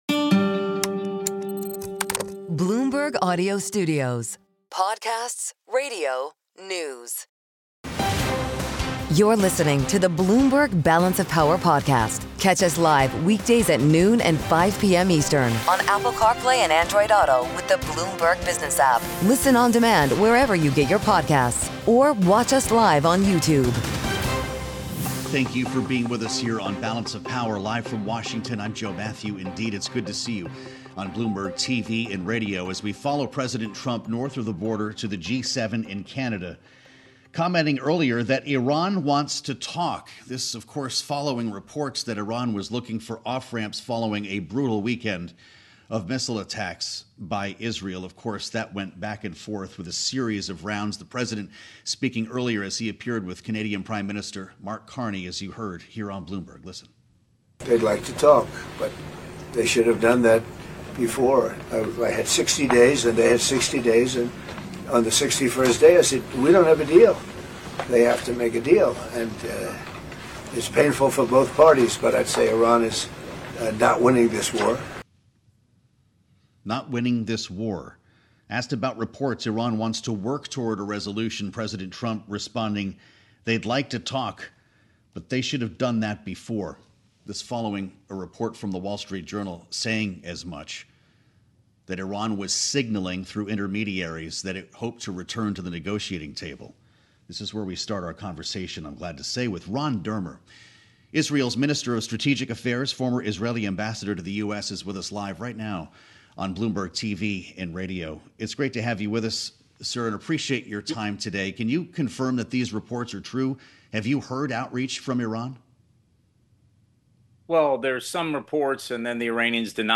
including conversations with influential lawmakers and key figures in politics and policy.